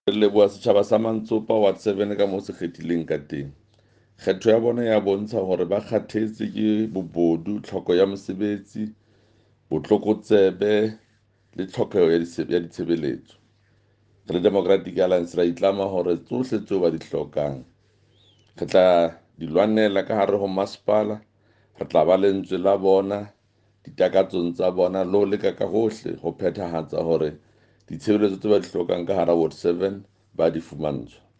Sesotho soundbites by Jafta Mokoena MPL.